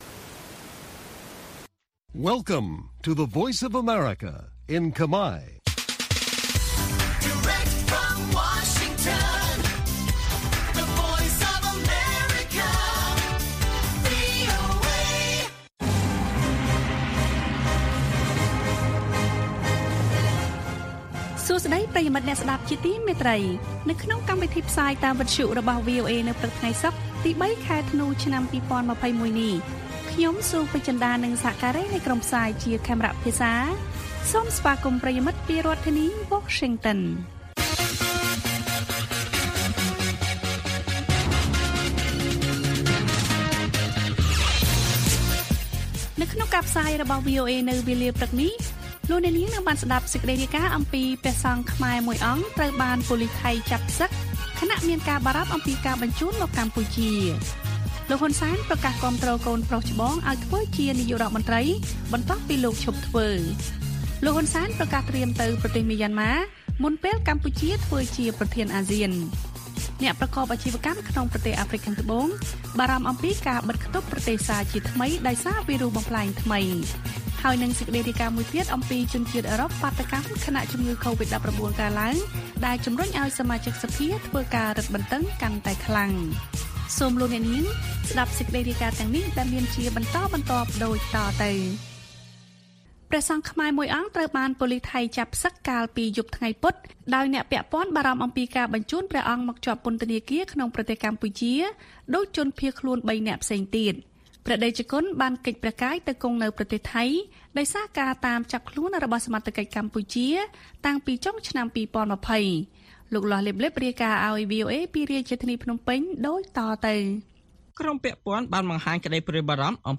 ព័ត៌មានពេលព្រឹក៖ ៣ ធ្នូ ២០២១